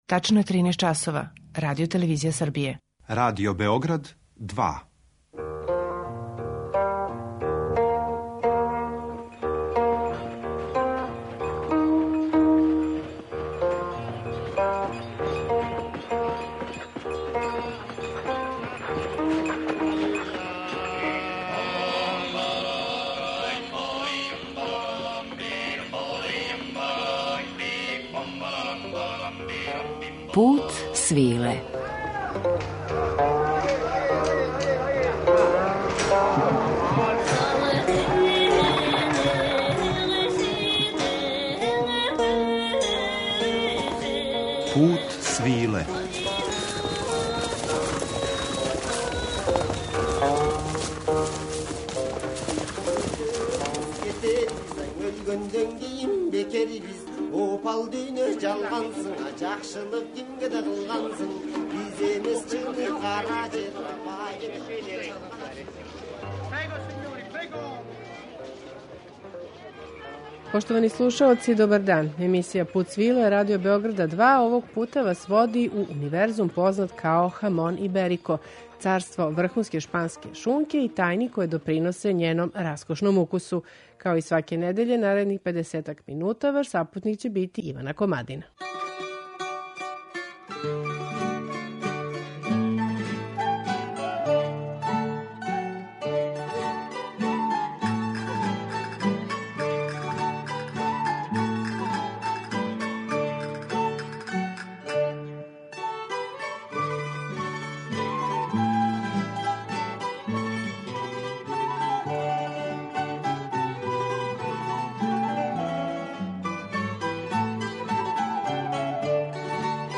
Данашњи Путу свиле води у тајни свет најбоље шпанске пршуте, уз музику из руралних области из целе Шпаније: Ла Манче, Галиције, Андалузије, Вера, села у близини Тарифе, Гвадалахаре, Сеговије, Мурсије, Оливенсе.
Пут свиле, као јединствено “радијско путовање”, недељом одводи слушаоце у неку од земаља повезаних са традиционалним Путем свиле, уз актуелна остварења из жанра “World music” и раритетне записе традиционалне музике.